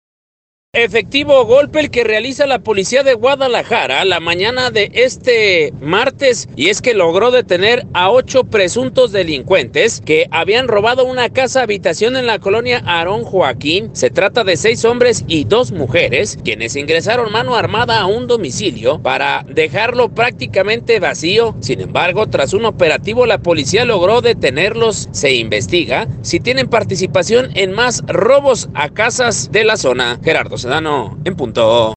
Concluye con el mes de agosto, la suspensión de la vida nocturna en Jalisco, implementada por el Gobierno del Estado, para disminuir los casos positivos de Covid entre los jóvenes. Al respecto habla, Enrique Alfaro, gobernador de Jalisco: